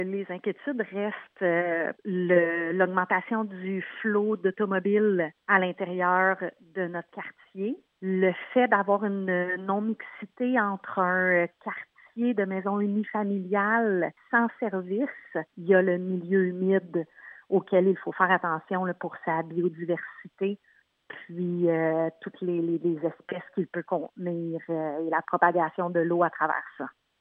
Cette semaine se tenait une conférence de presse dans le quartier des Oliviers, de Granby, concernant les inquiétudes des résidents au sujet du projet de développement prévu dans le boisé voisin, connu sous le nom des Terres Lamoureux.